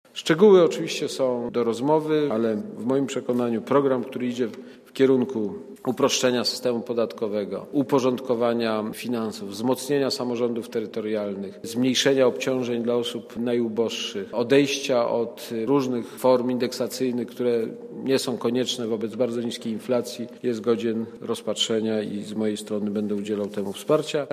Jestem przekonany że wszystkie rozsądne siły polityczne w Polsce pomoga temu programowi - mówił po posiedzeniu Rady Gabinetowej Aleksander Kwaśniewski.
Mówi prezydent Aleksander Kwaśniewski (190 KB)